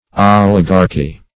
Oligarchy \Ol"i*gar"chy\, n.; pl.